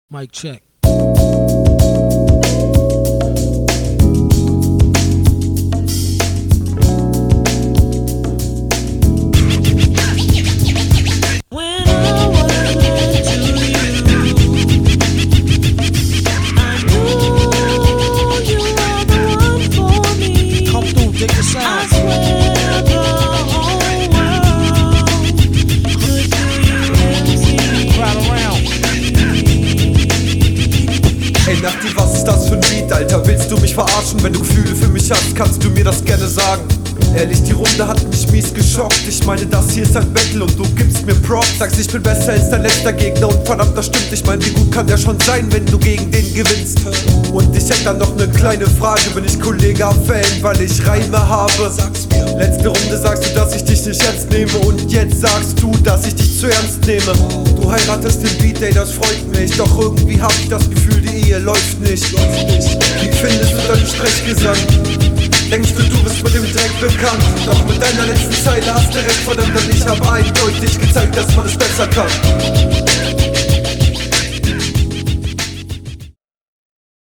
Du kämpfst hier ziemlich mit dem Beat, die Mische hat sich im Gegensatz zu deiner …